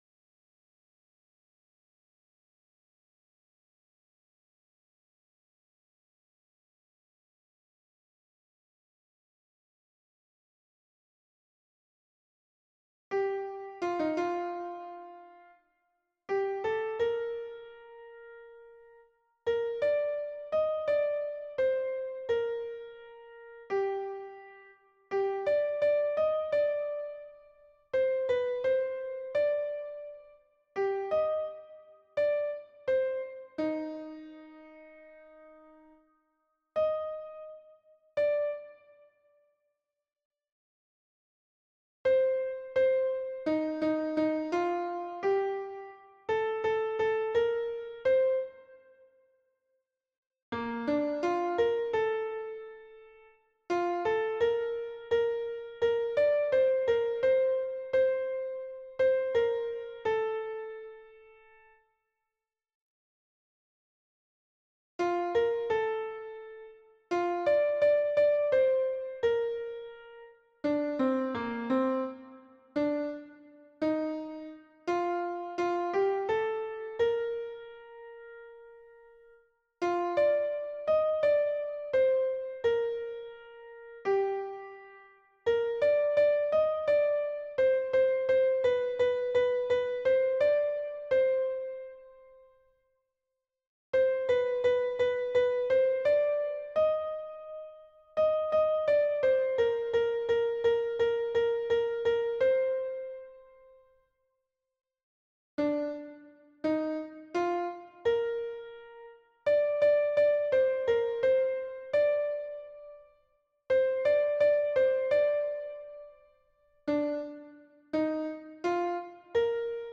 MP3 version piano - vitesse réduite pour apprentissage
Soprano